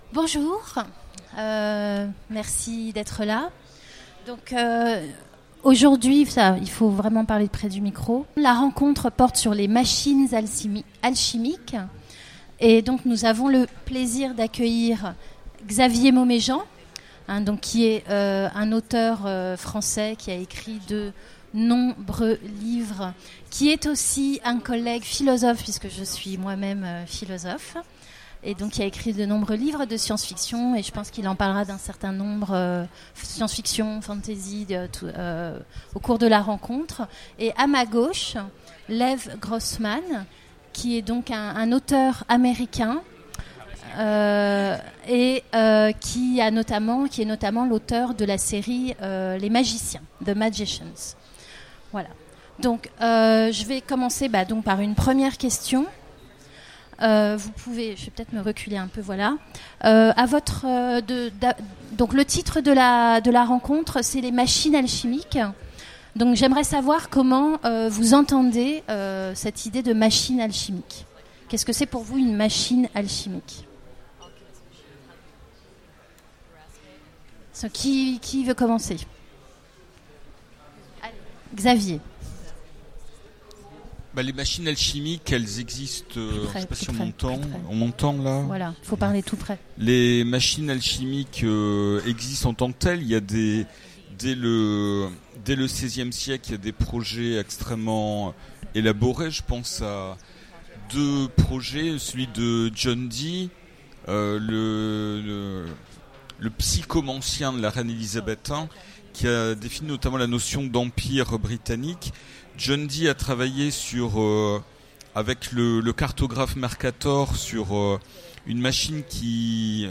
Utopiales 2016 : Conférence Les machines alchimiques